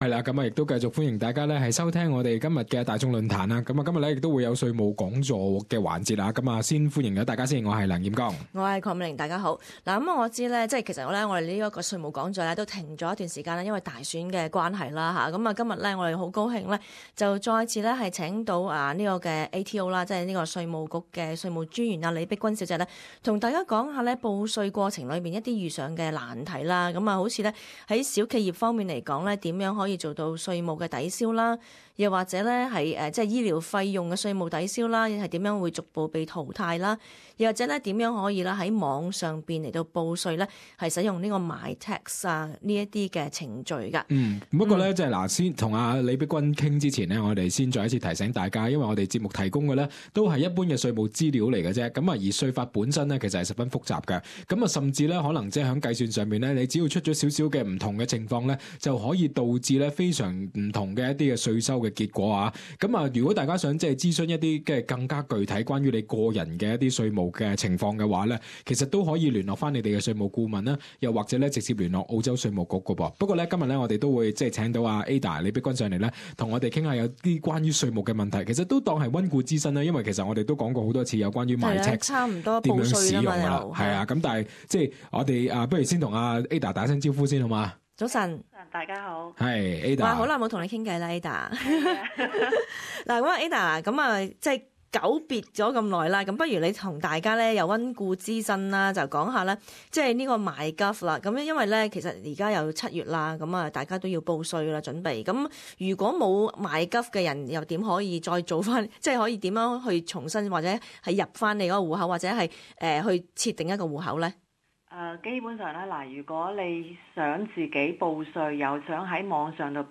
『大众论坛』 - 税务讲座